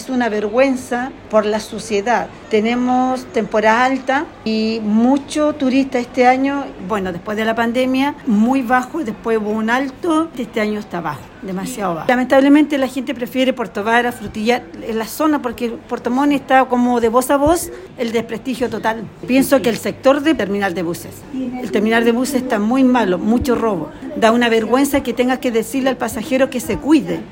La Radio conversó con diversos trabajadores y pasajeros al interior del rodoviario, quienes comentaban la falta de seguridad en el recinto, como la principal causante de los problemas que se registran.
De igual manera, una guía turística que se ubica en el terminal lamentó que con el aumento de turistas, las condiciones del rodoviario no estén a la altura.
operadora-turistica.mp3